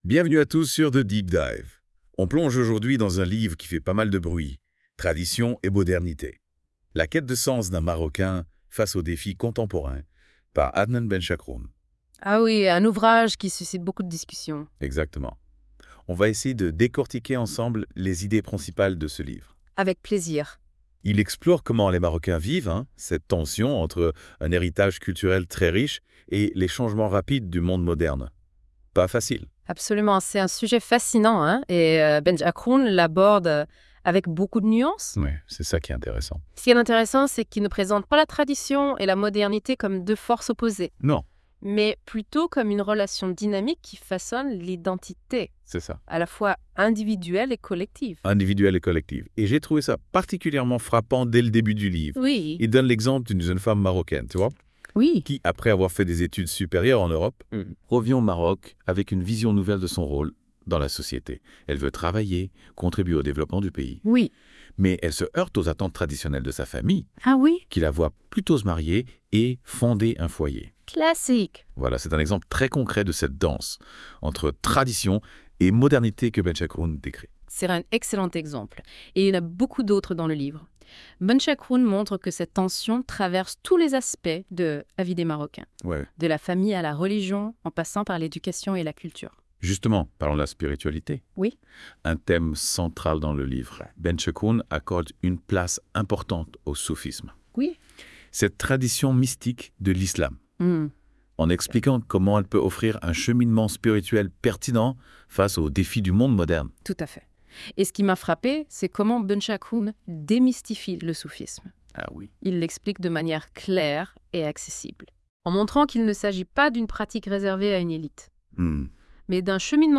Débat (74.04 Mo)